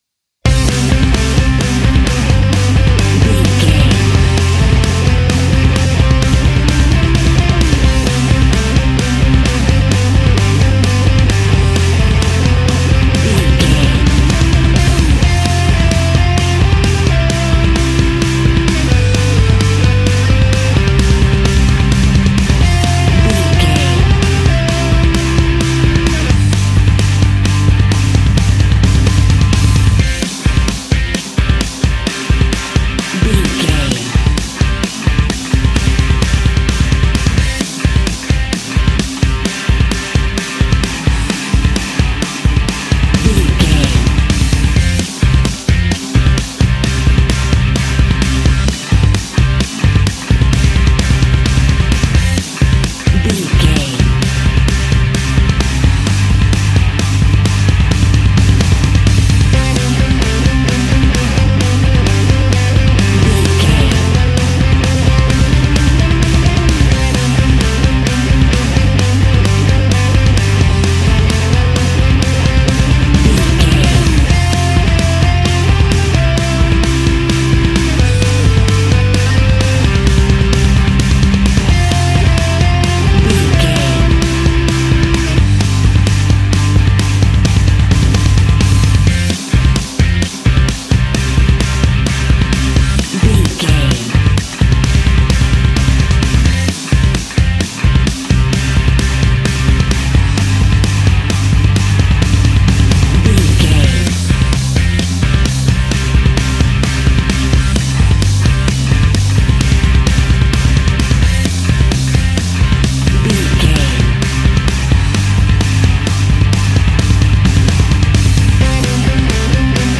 Epic / Action
Ionian/Major
hard rock
distortion
punk metal
rock guitars
Rock Bass
Rock Drums
heavy drums
distorted guitars
hammond organ